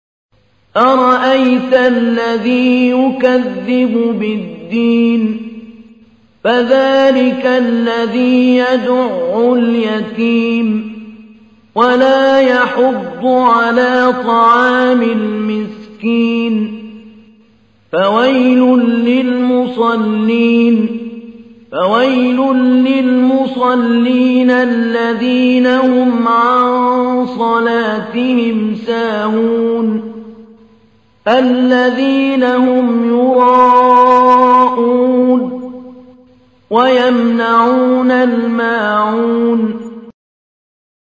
تحميل : 107. سورة الماعون / القارئ محمود علي البنا / القرآن الكريم / موقع يا حسين